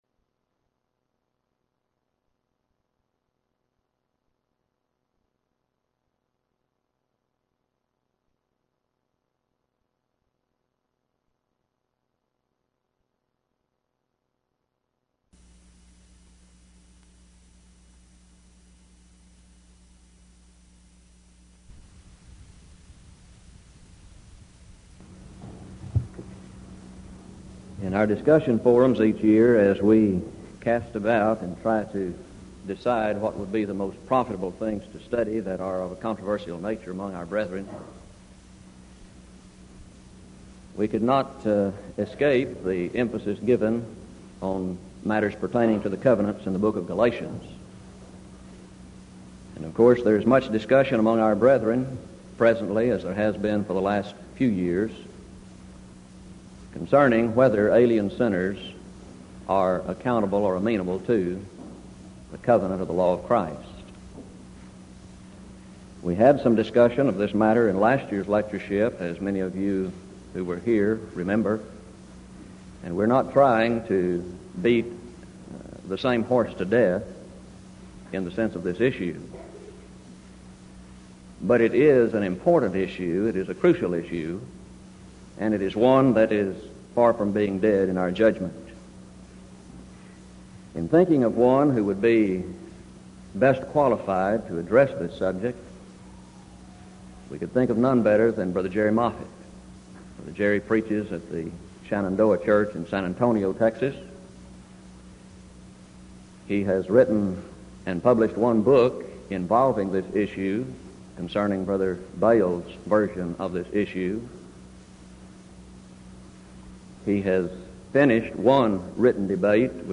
Title: Discussion Forum
Event: 1986 Denton Lectures Theme/Title: Studies in Galatians